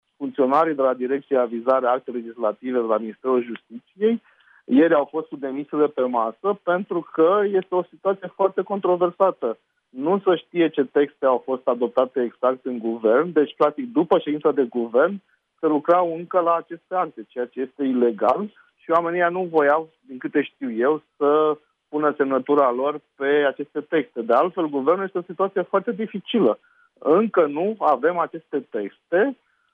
Eurodeputatul de la USR mai spune că situaţia inedită a creat rumoare chiar şi la Ministerul Justiţiei unde mai mulţi funcţionari ar fi ameninţat că-şi dau demisia: